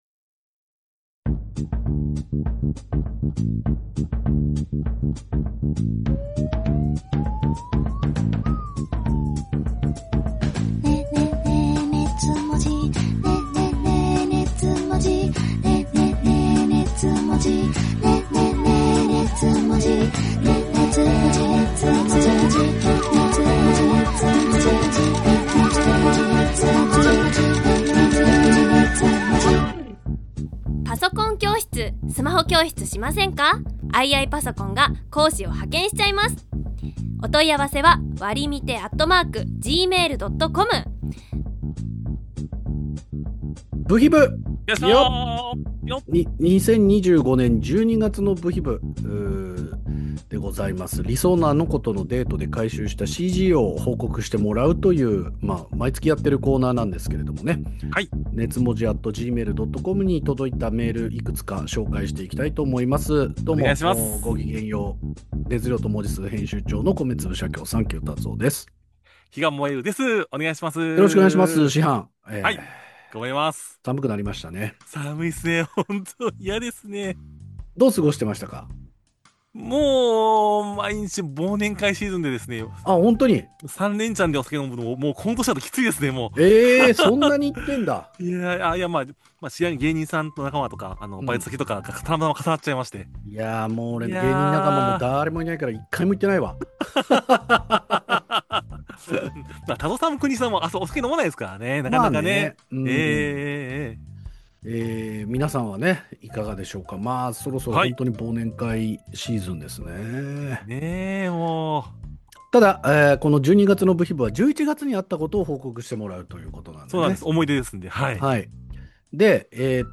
二次元を哲学するトークバラエティ音声マガジン